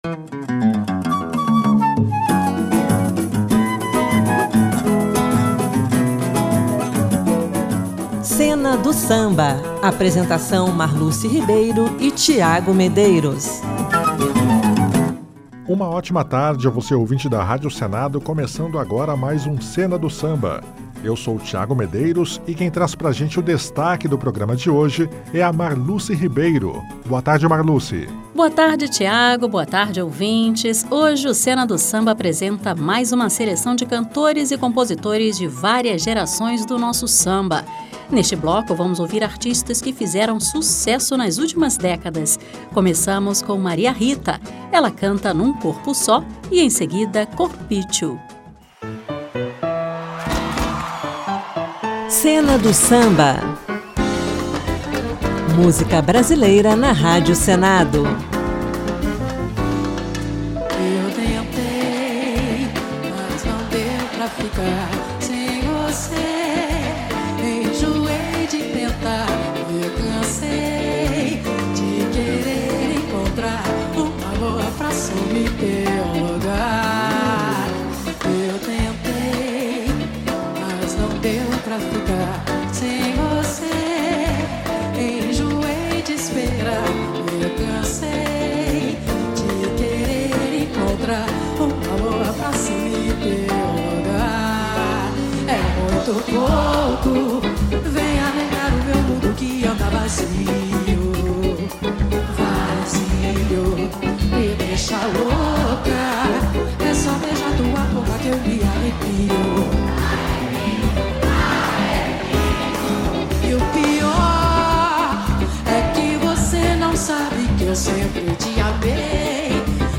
sambas